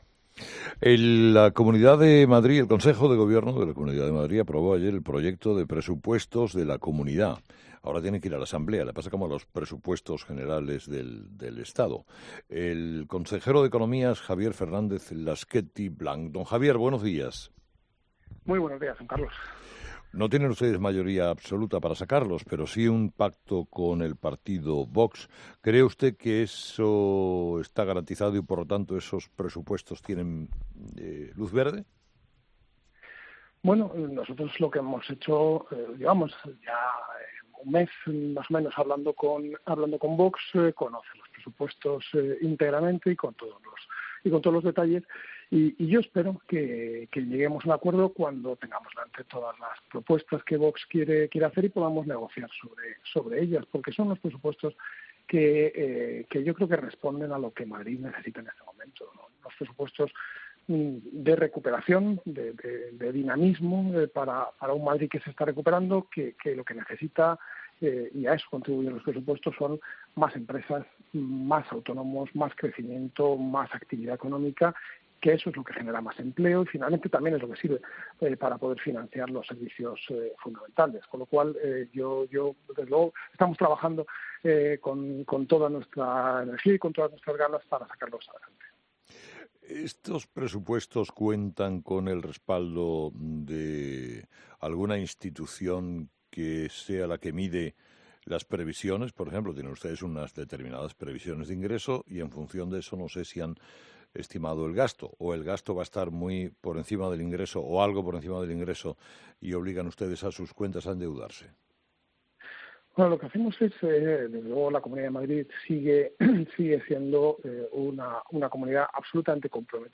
El consejero de Economía y Hacienda de la Comunidad de Madrid, Javier Fernández-Lasquetty, ha pasado por Herrera en COPE para defender los presupuestos de la región, que el Partido Popular está negociando con su socio de investidura, Vox.
Con Carlos Herrera